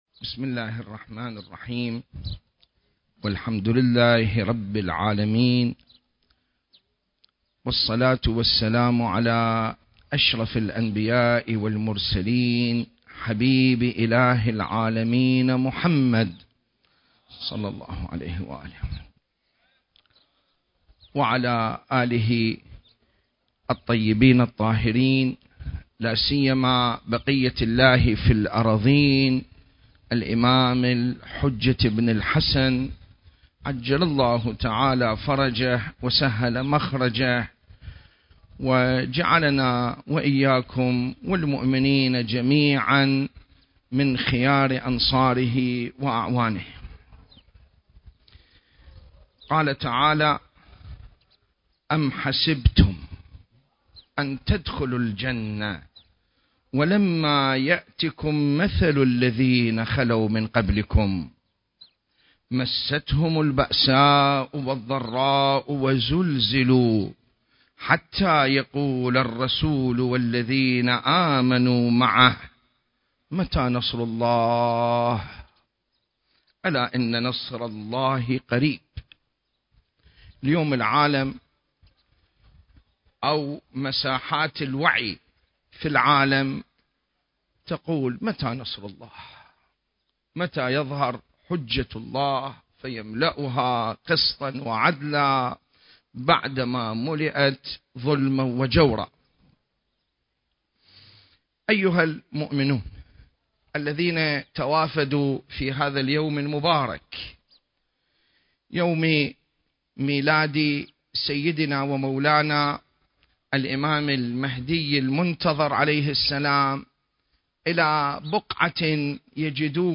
المكان: مسجد السهلة المعظم